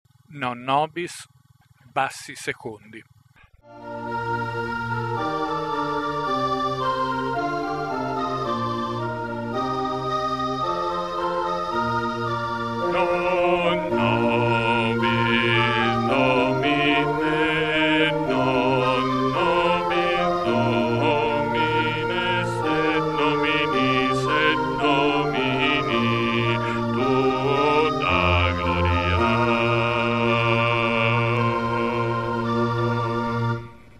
Non nobis - Bassi 2 + Base.mp3